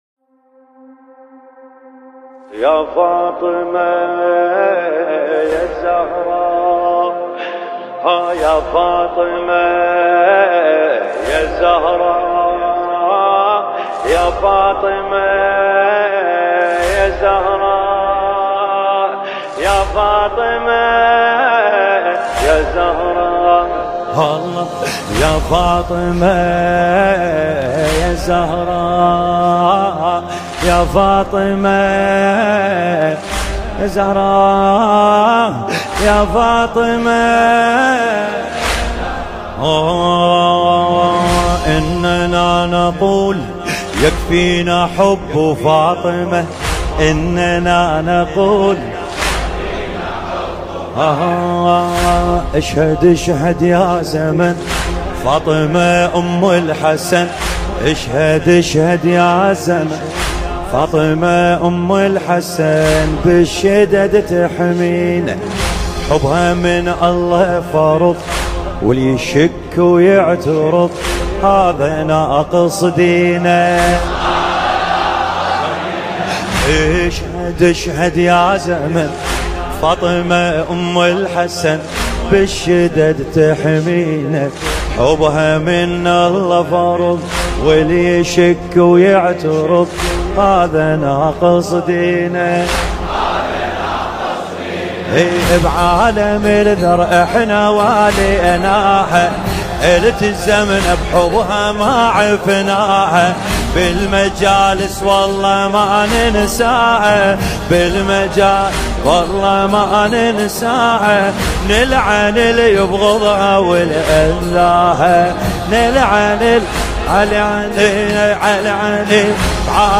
نوحه ایام فاطمیه عربی